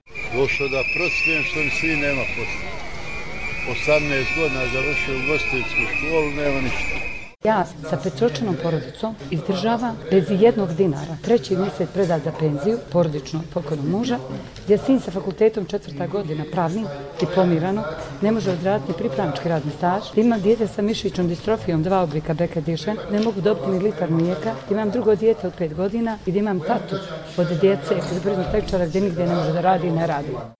Koji su ih razlozi izveli na ulice i naveli da učestvuju u radu plenumâ građanâ, neki od učesnika tih skupova su nam rekli:
Građani o svom učešću u plenumu